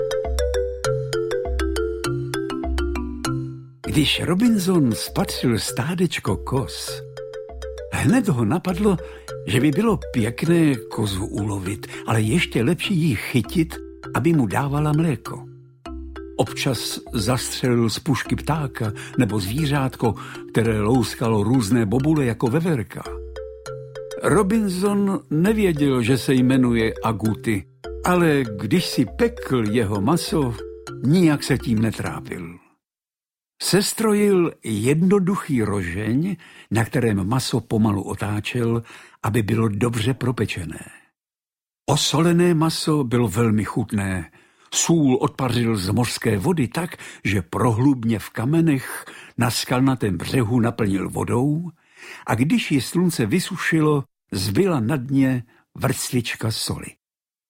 Audiokniha
Čte: Vladimír Brabec